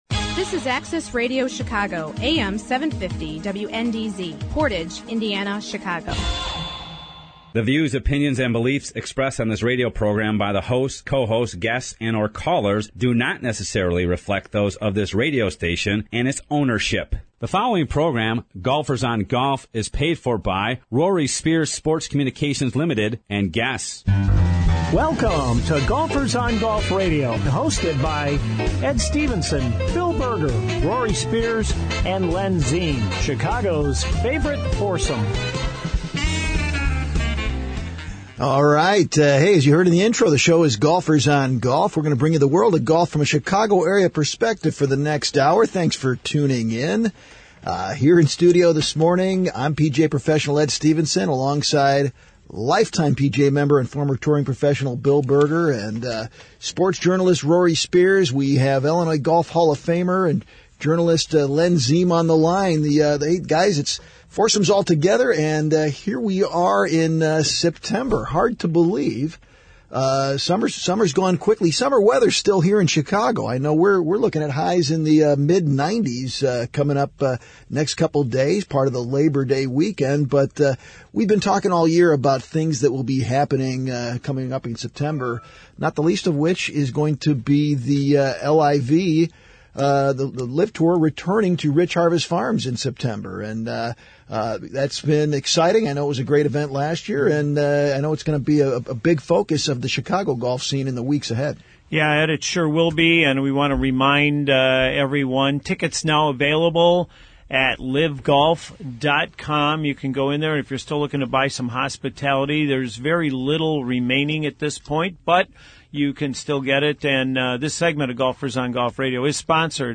But you will hear from PGA Tour’s Lucas Glover in his interview with the Golfers on Golf, talking the LAB Golf putter and how it’s saved his career on the PGA Tour.